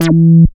70.08 BASS.wav